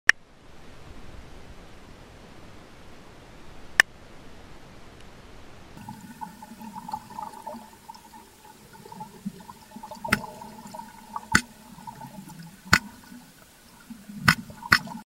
Stingrays Recorded Making Sounds In sound effects free download
Stingrays Recorded Making Sounds In The Wild For The First Time